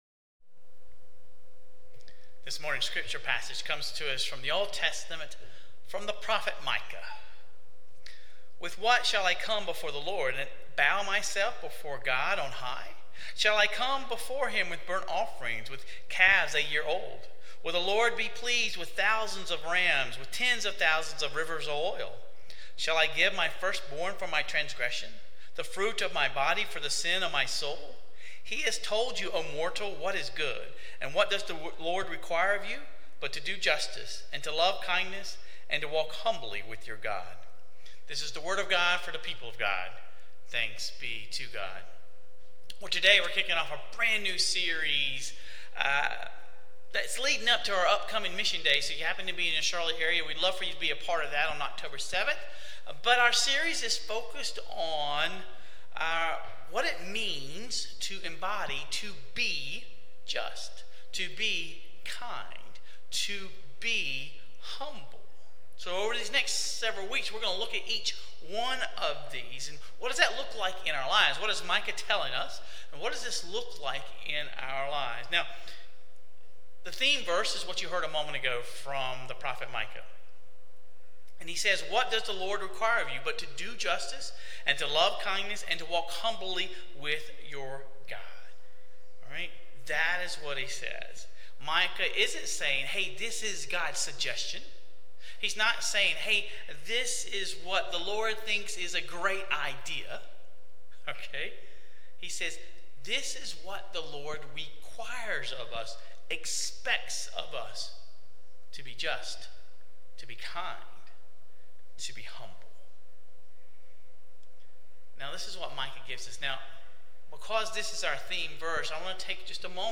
His answer was surprisingly simple: Do justice, love kindness, and walk humbly with God. In today's sermon, we explore the first of these calls: Justice.